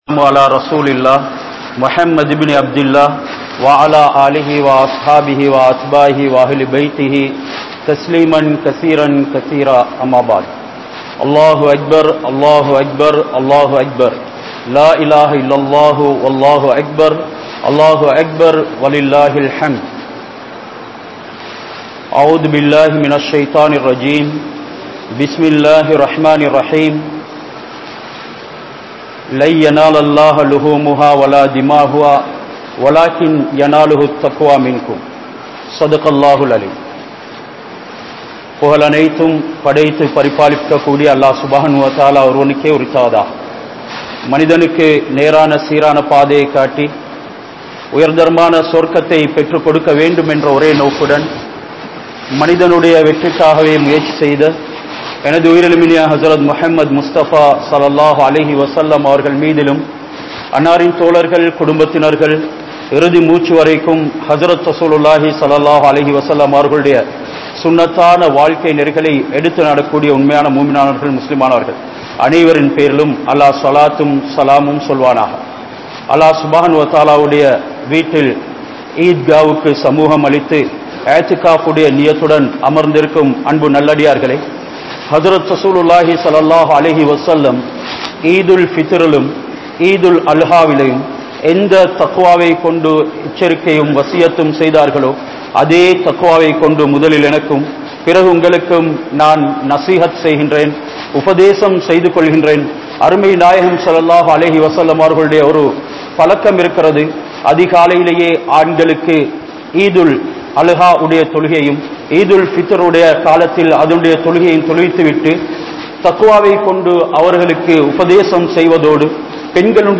Thaqwa (தக்வா) | Audio Bayans | All Ceylon Muslim Youth Community | Addalaichenai
Kattukela Jumua Masjith